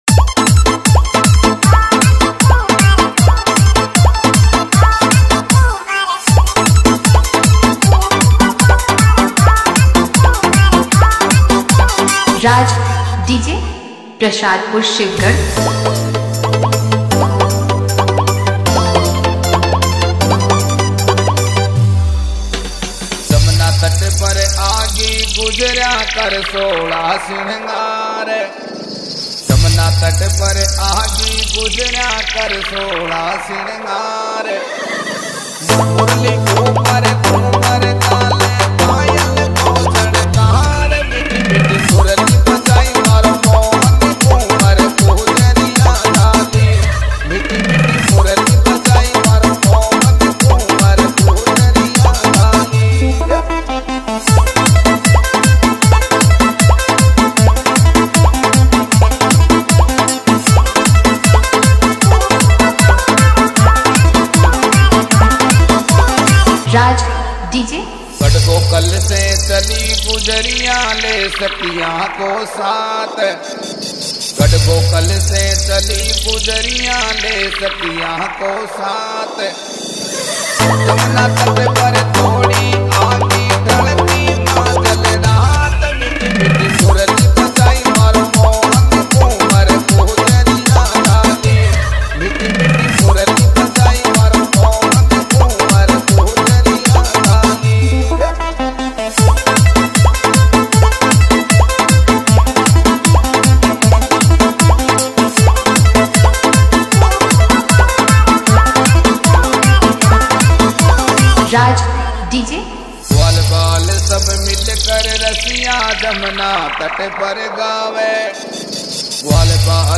Krishna Trance Mix Dj
Bhakti Remix Song
Competition Dj Beat